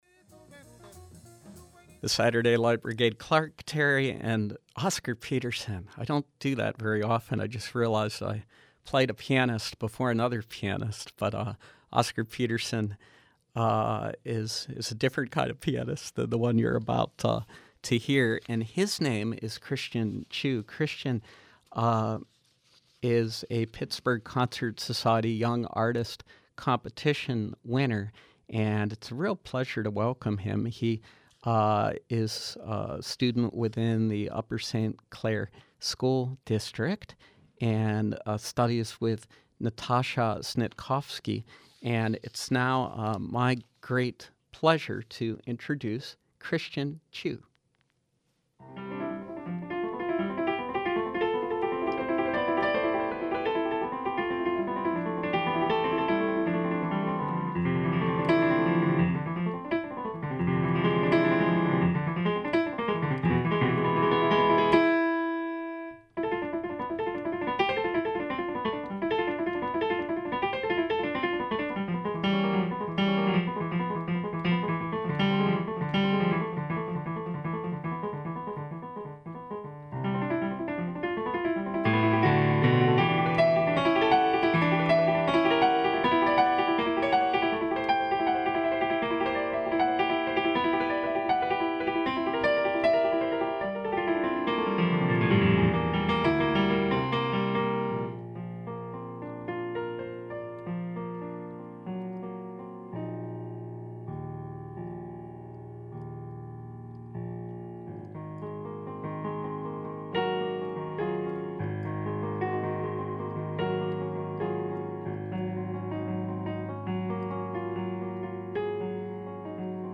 The Pittsburgh Concert Society’s Young Artists Competition provides a recital opportunity and support to talented classical musicians.
performing selections on piano, live on SLB.